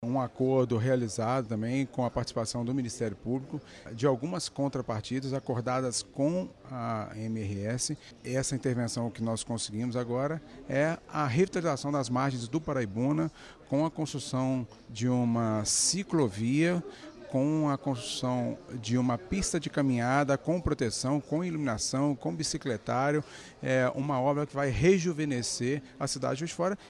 Uma audiência pública sobre concessão de ferrovias foi realizada na Câmara Municipal.
Conformeo autor do pedido da audiência, o deputado estadual Noraldino Junior (PSC), foi feito um acordo com a empresa, que não faz parte do valor da concessão.